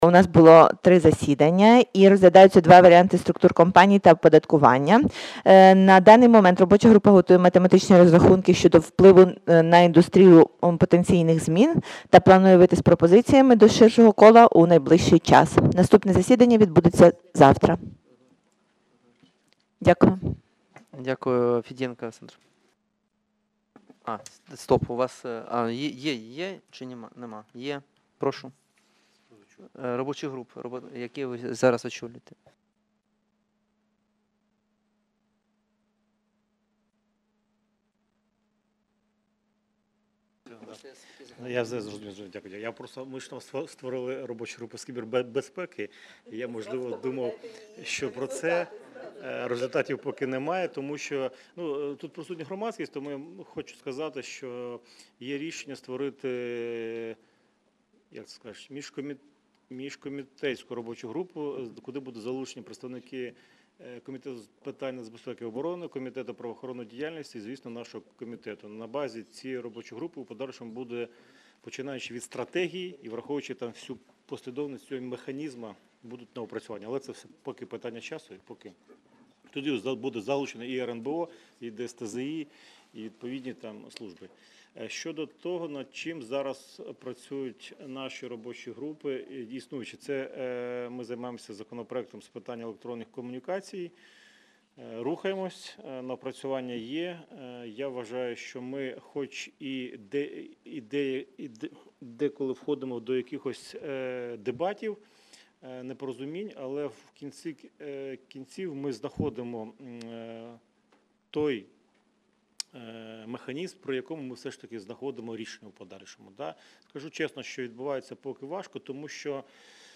Аудіозапис засідання Комітету від 04.12.2019